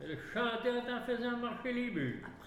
Il chante pour faire avancer les bœufs
Maraîchin